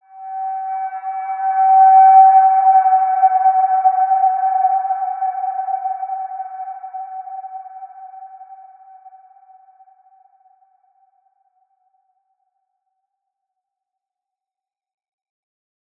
Large-Space-G5-p.wav